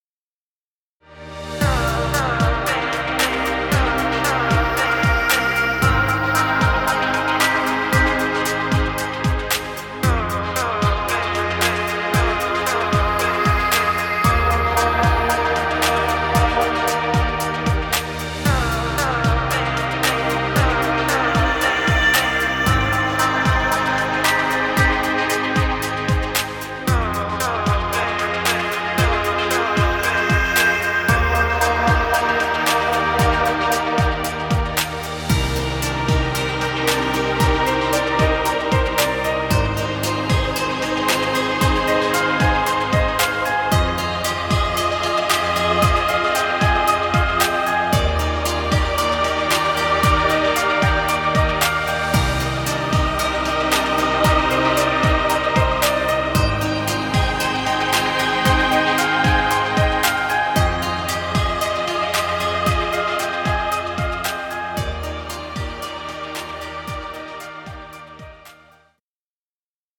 Chillout music.